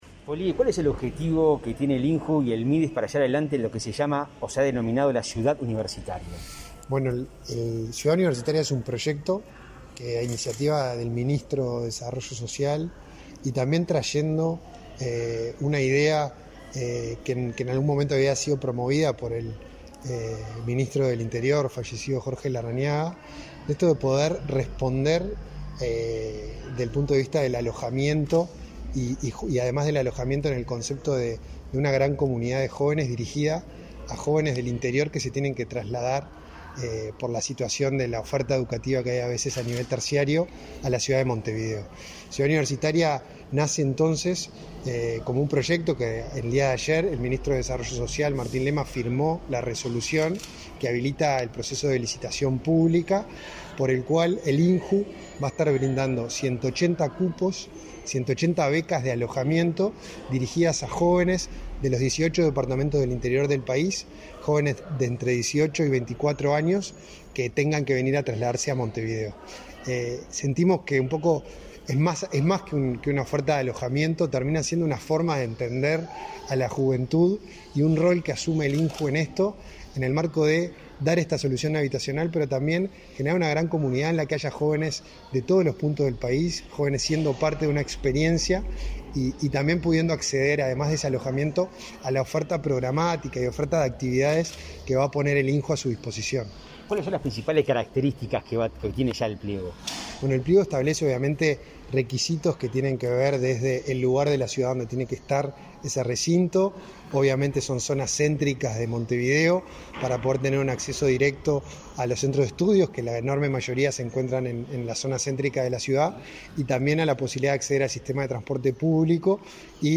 Entrevista al director del INJU, Felipe Paullier